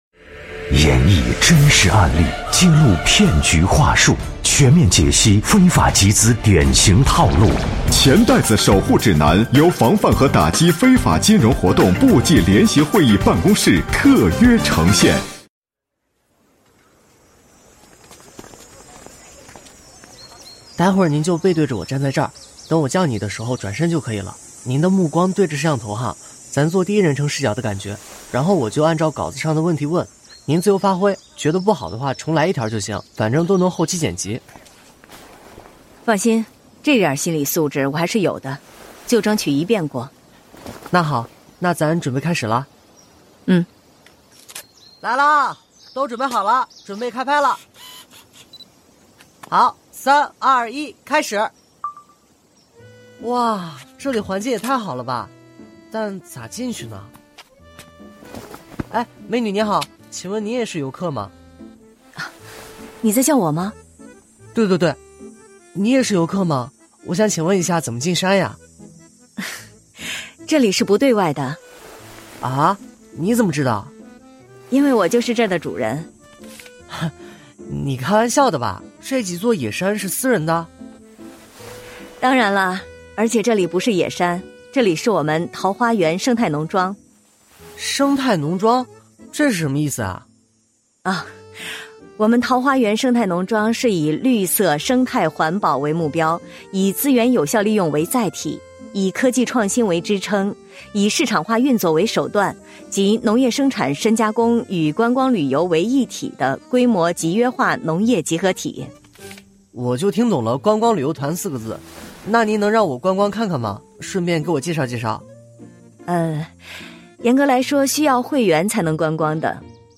《钱袋子守护指南》栏目丨第十七集 桃花源也能赚钱 来源：防范和打击非法金融活动部际联席会议办公室和云听联合呈现 时间：2025-02-05 18:35 微信 微博 QQ空间 《钱袋子守护指南》是经济之声联合防范和打击非法金融活动部际联席会议办公室特别策划推出的一档防范非法集资科普栏目。选取真实案例，透过典型情节演示非法集资对个人和社会带来的危害，更生动地传递“反非”的理念，增强社会的风险意识和预防能力。